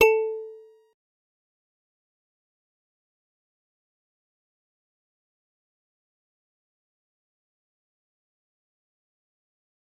G_Musicbox-A4-pp.wav